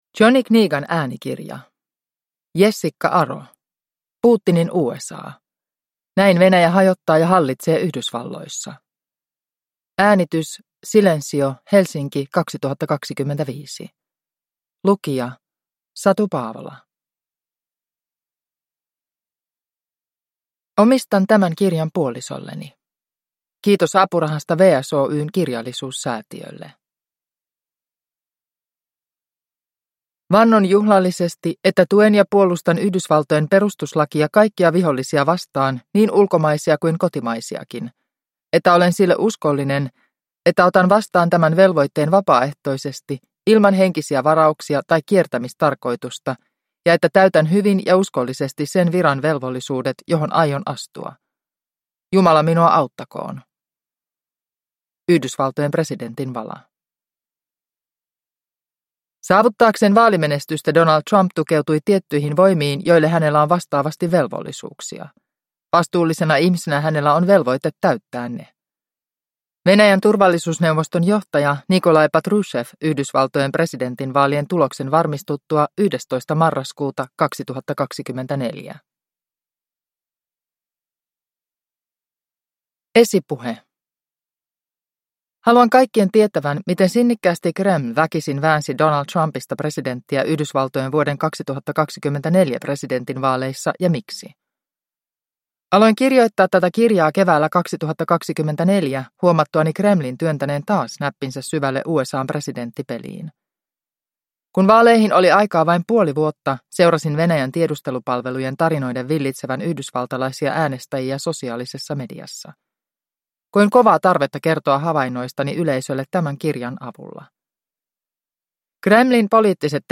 Putinin USA – Ljudbok